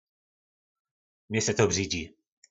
Slovník nářečí Po našimu
Štítit (se) - Břidźič (se) 🔉